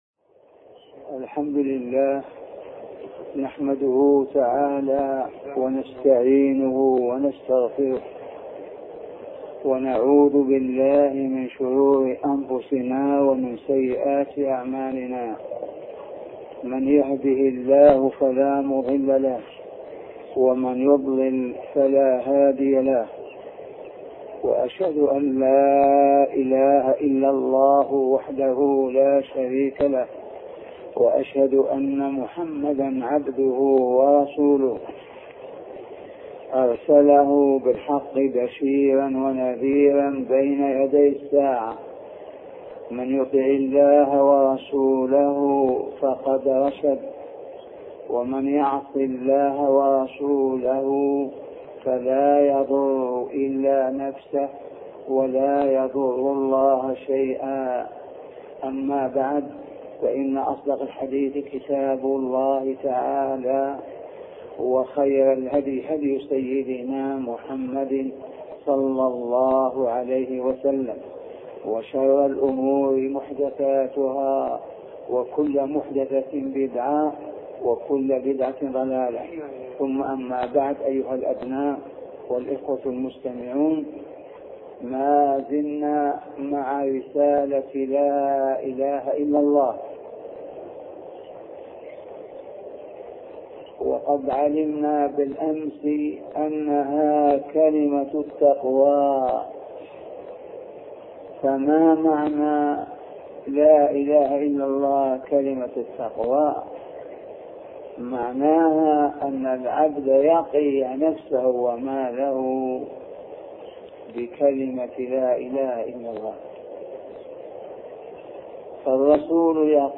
سلسلة محاطرات بعنوان معنى لا إله إلا الله